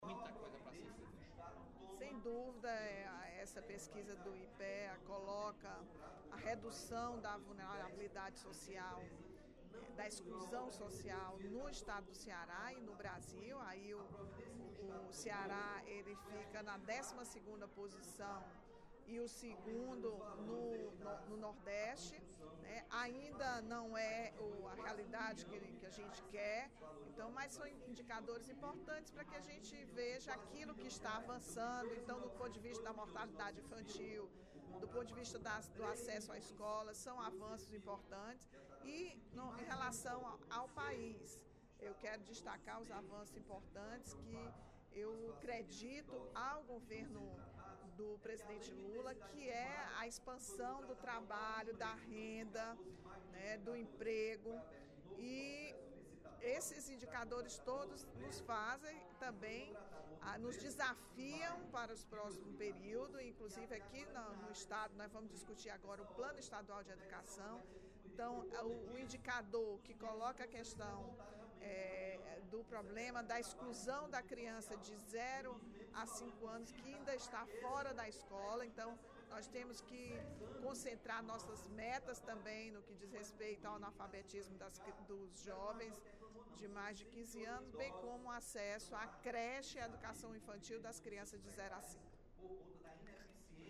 A redução do índice de exclusão social no Ceará para 28,7%, destaque da edição de hoje do jornal Diário do Nordeste, foi celebrada pela deputada Rachel Marques (PT), no primeiro expediente da sessão plenária da Assembleia Legislativa desta quarta-feira (02/09).
Dep. Rachel Marques (PT) Agência de Notícias da ALCE